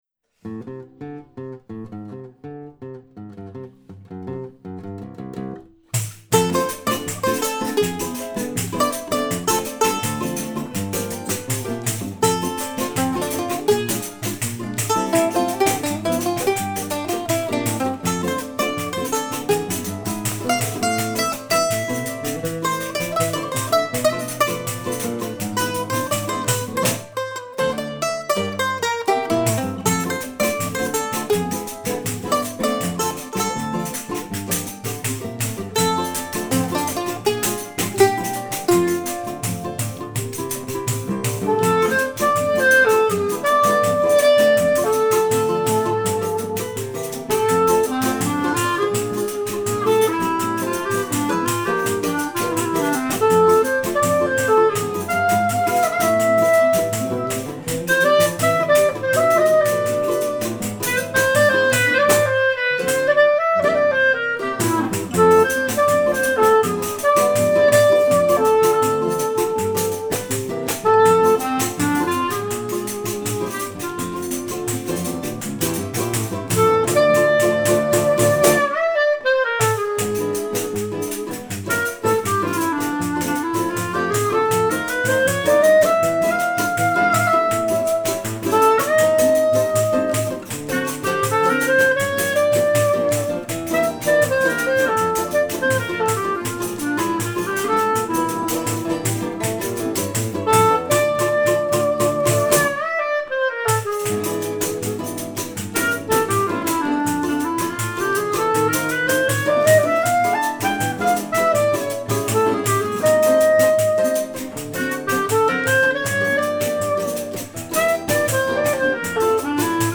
après une séance de travail Choro